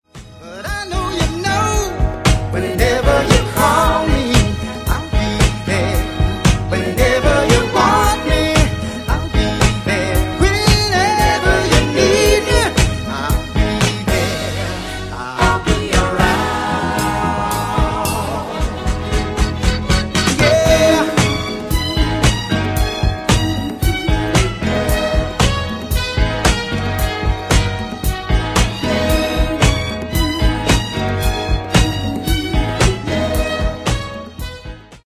Genere:   Disco | Soul | Funk
10''Acetate Remix